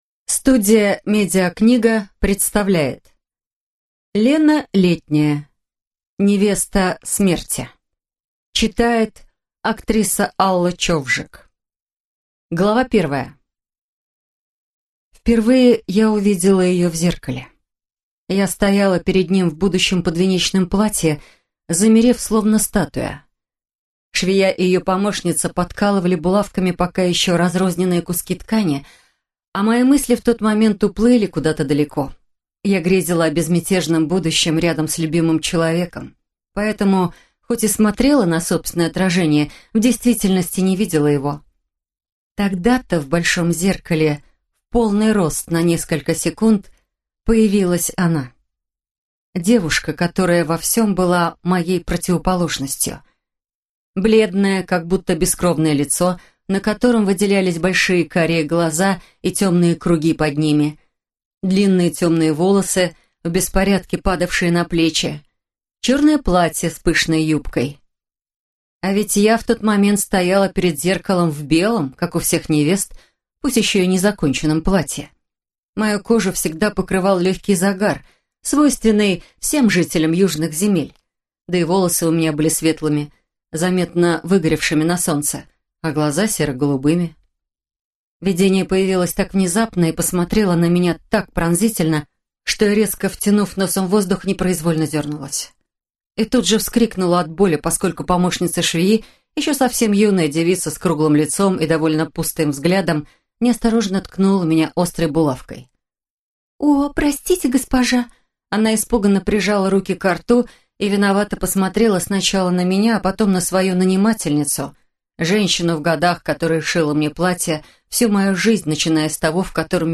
Студия «МедиаКнига» представляет аудиокнигу «Невеста Смерти» популярной российской писательницы Лены Обуховой.